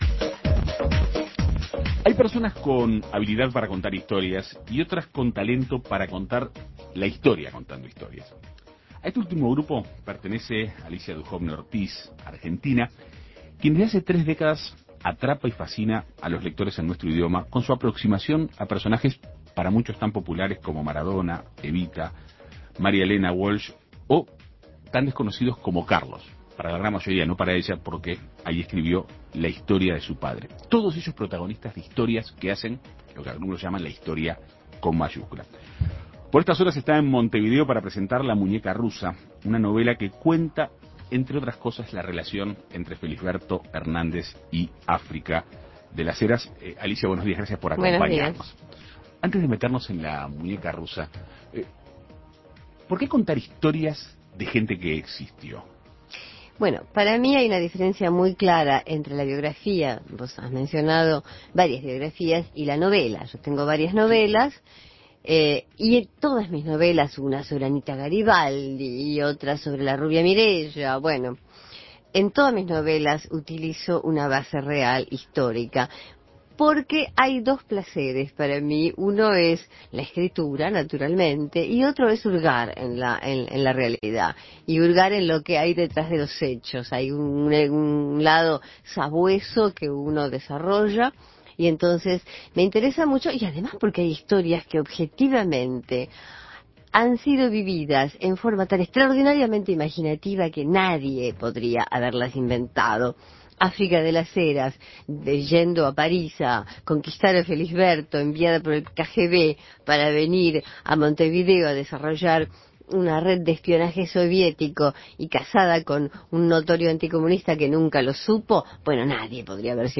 Dujovne se encuentra en Montevideo para presentar "La Muñeca Rusa". Sobre los detalles de esta obra dialogó con En Perspectiva Segunda Mañana.